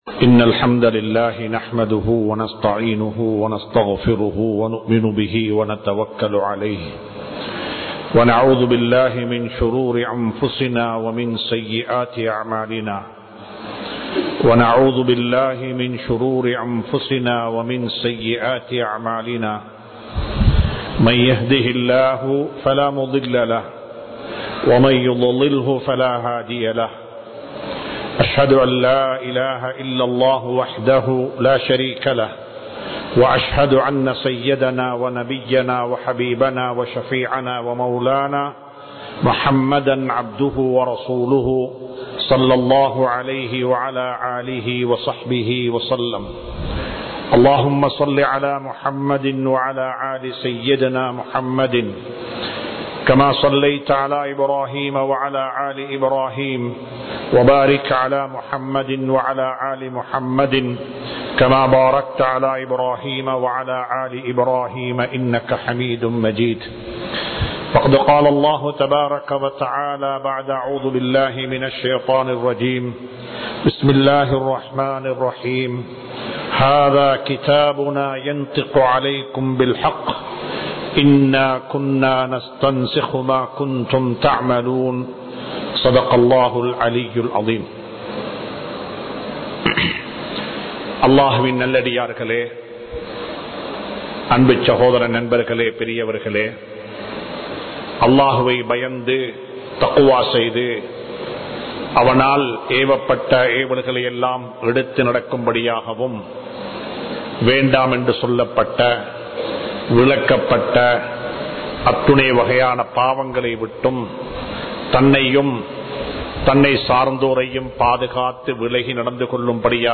மறுமைக்காக எவற்றை தயார் செய்துள்ளோம்? | Audio Bayans | All Ceylon Muslim Youth Community | Addalaichenai
Rathmalana Jumua Masjidh